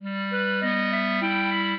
clarinet
minuet12-10.wav